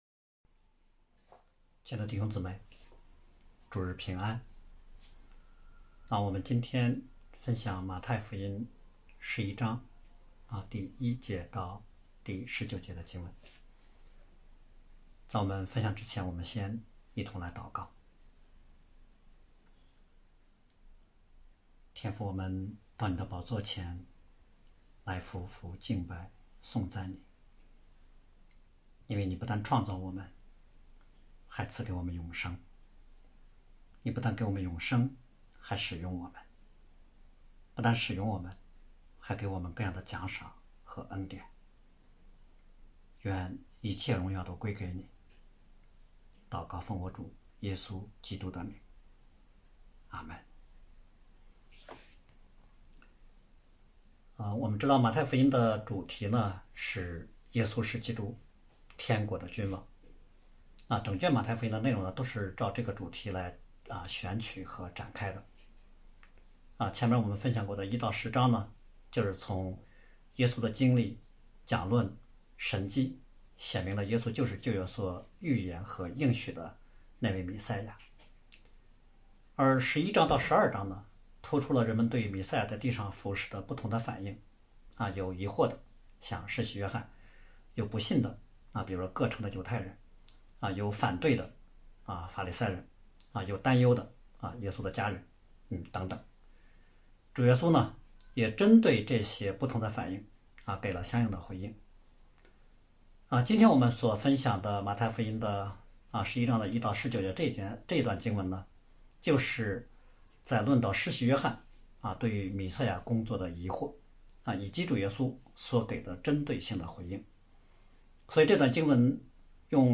北京守望教会2025年6月1日主日敬拜程序